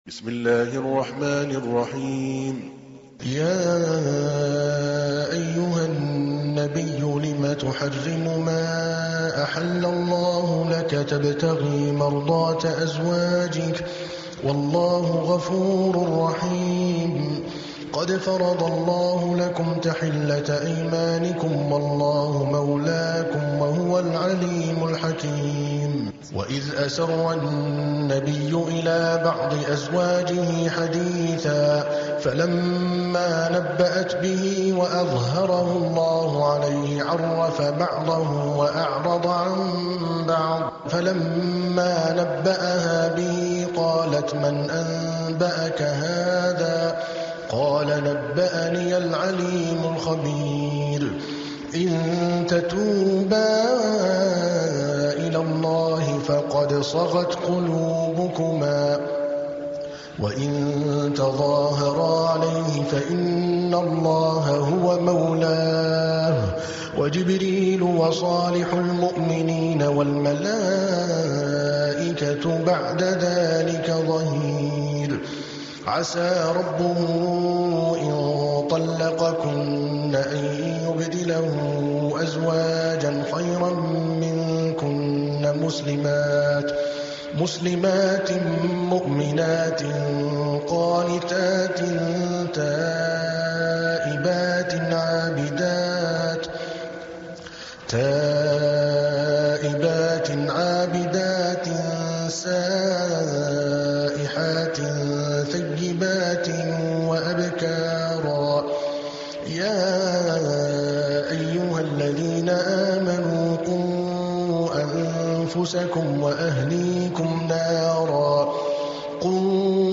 66. Surah At-Tahr�m سورة التحريم Audio Quran Tarteel Recitation
Surah Recitations with Sheikh Adel Al-Kalbani
Surah Repeating تكرار السورة Download Surah حمّل السورة Reciting Murattalah Audio for 66.